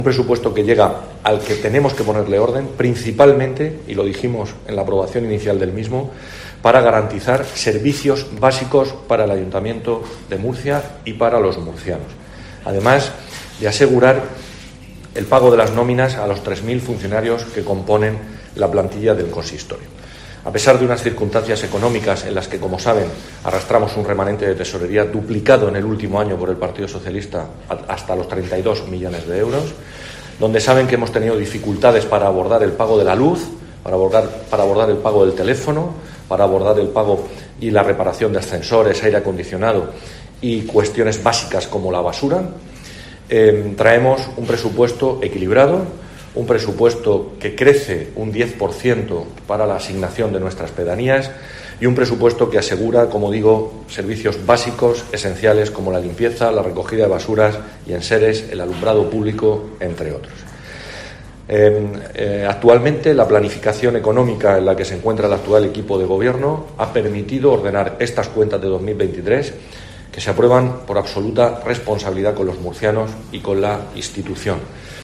José Francisco Muñoz, concejal de Hacienda del Ayuntamiento de Murcia